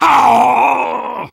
Voice file from Team Fortress 2 Russian version.
Soldier_painsevere02_ru.wav